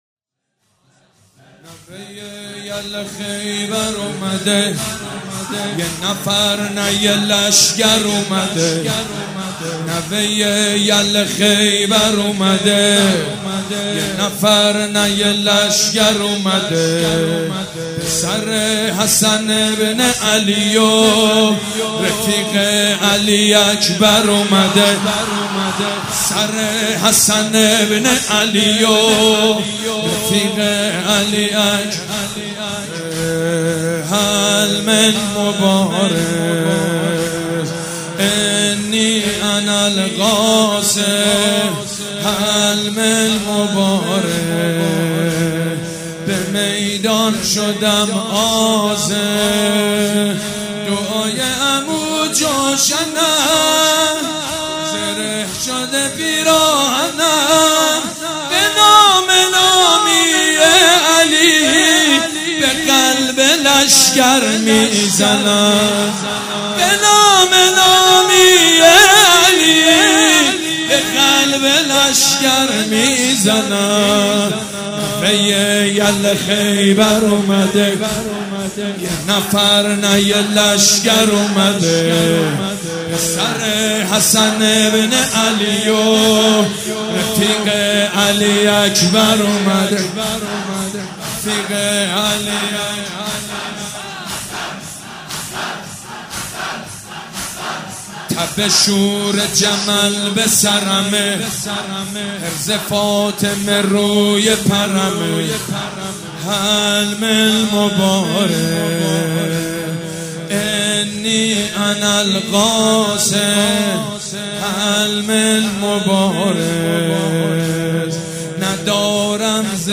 شب ششم محرم الحرام‌ جمعه ۱6 مهرماه ۱۳۹۵ هيئت ريحانة الحسين(س)
سبک اثــر زمینه
مراسم عزاداری شب ششم